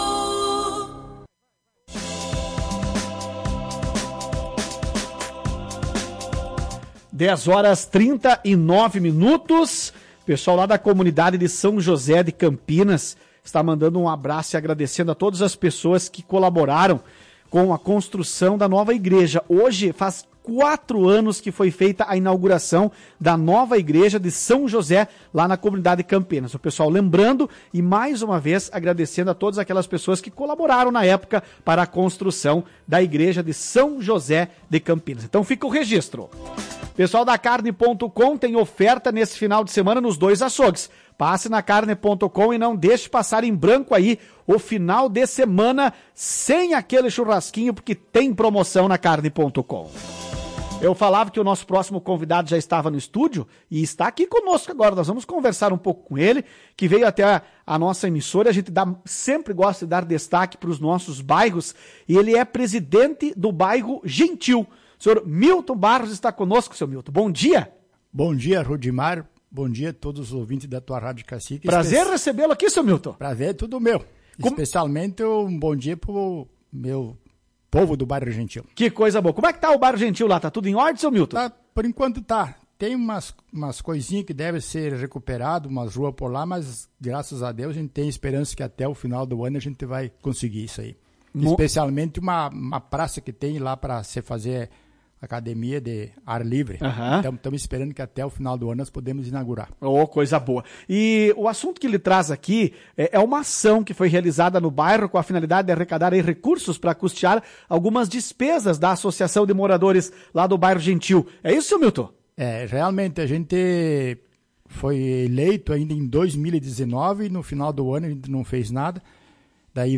Em entrevista à Tua Rádio ele fala sobre o andamento das atividades no bairro Gentil.